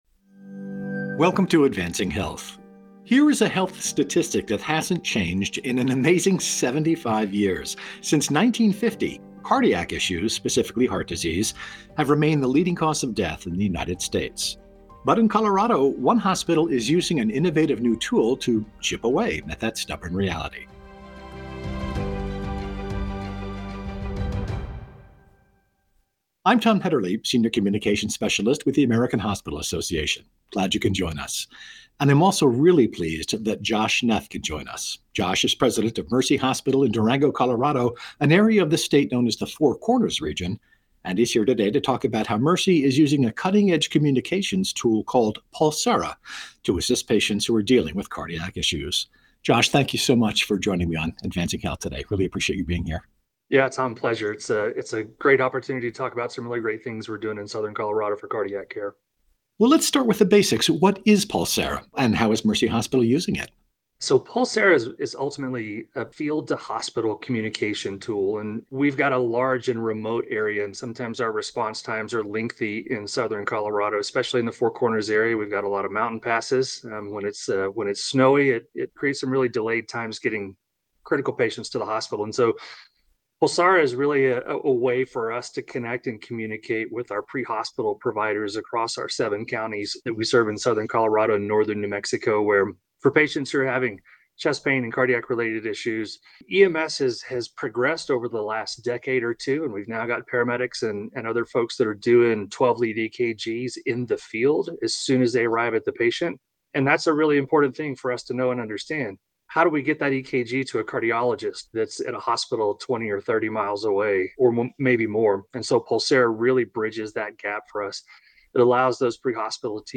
AHA Podcast Interview Highlights/Recap